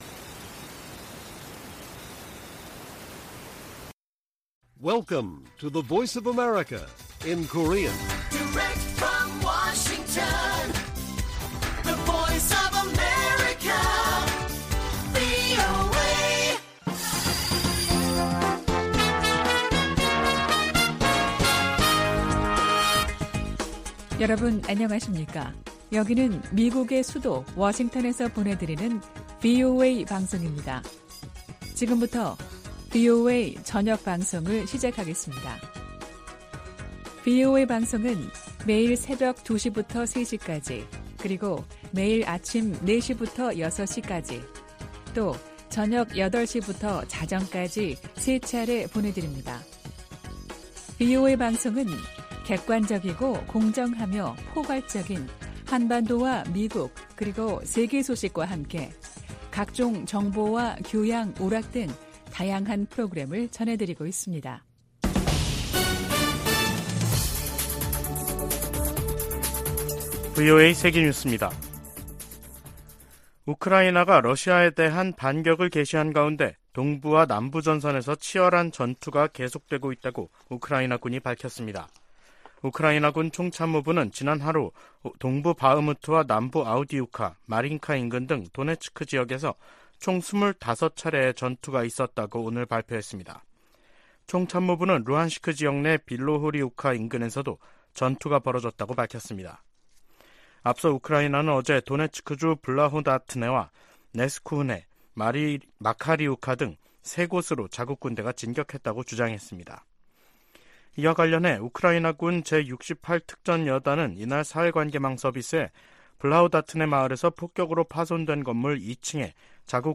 VOA 한국어 간판 뉴스 프로그램 '뉴스 투데이', 2023년 6월 12일 1부 방송입니다. 북한 열병식 훈련장에 다시 차량과 병력의 집결 장면이 관측됐습니다. 7월의 열병식 개최가 가능성이 주목되고 있습니다. 국제해사기구(IMO) 회원국들이 사상 첫 북한 미사일 발사 규탄 결의문을 받아들일 수 없다는 북한측 주장을 일축했습니다.